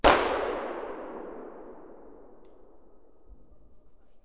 mech_fire2.ogg